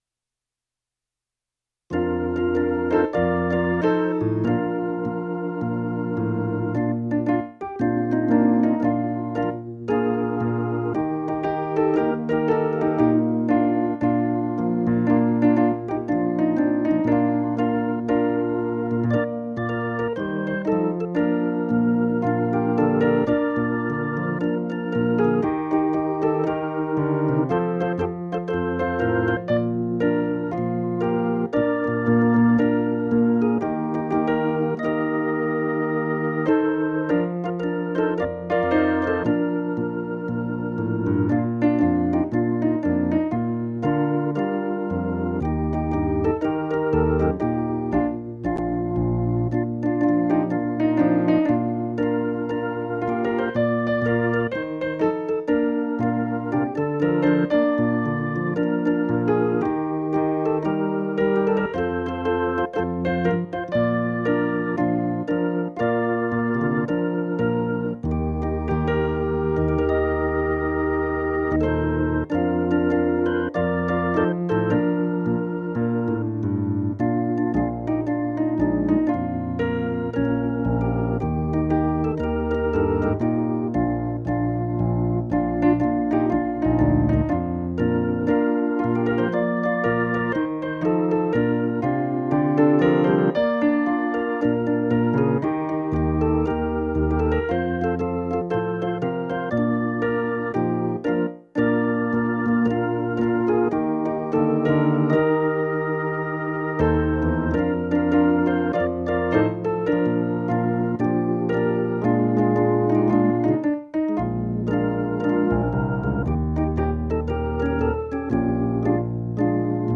playback-hino-31.mp3